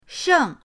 sheng4.mp3